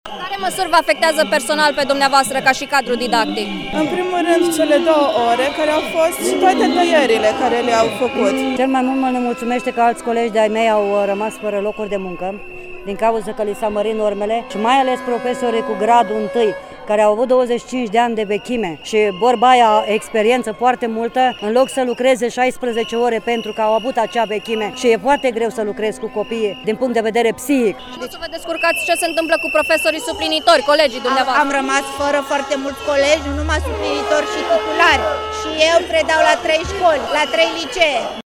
Oamenii au scandat “Demnitate!”, “Libertate!” sau “Unitate pentru Educaţie”.
Vox-protestatari.mp3